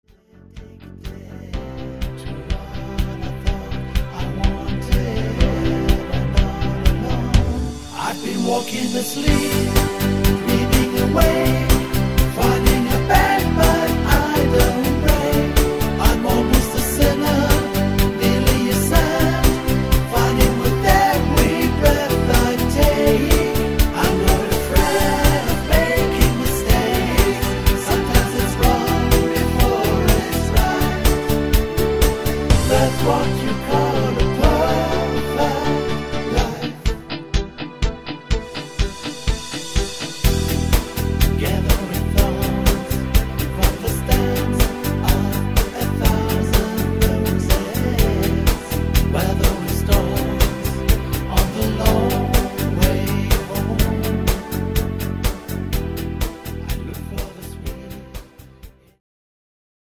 leichter zu singende Tonarten
Sänger Version